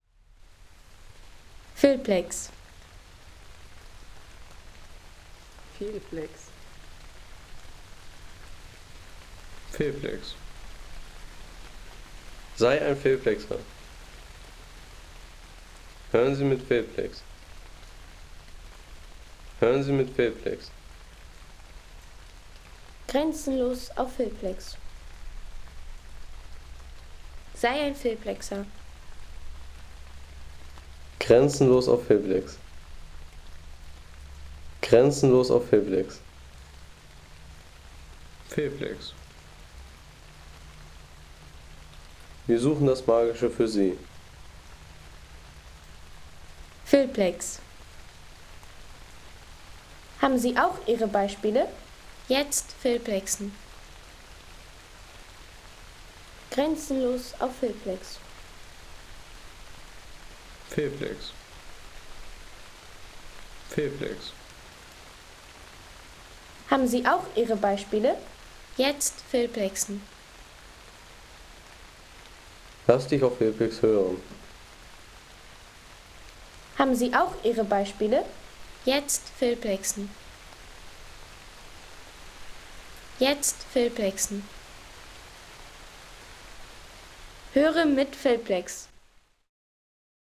Erholungsgenuss für alle, wie z.B. diese Aufnahme: Regentropfen auf Ahornblättern
Regentropfen auf Ahornblättern